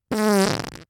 FART SOUND 41